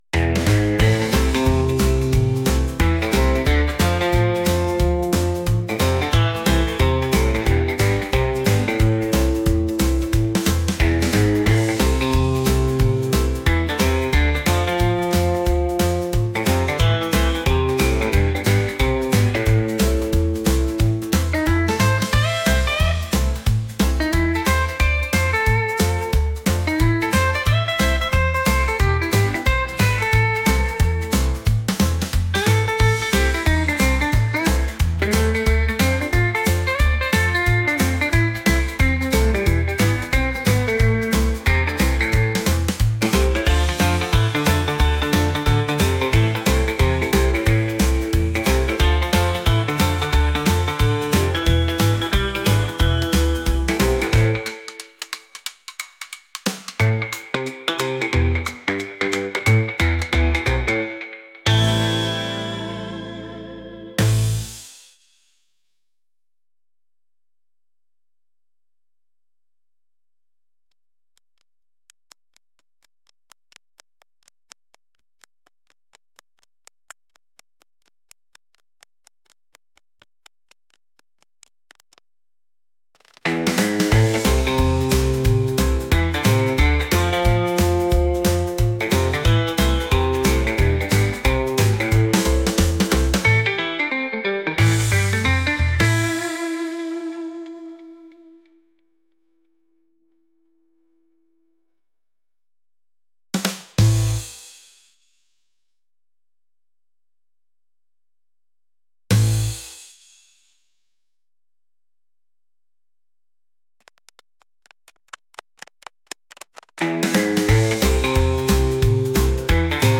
traditional | upbeat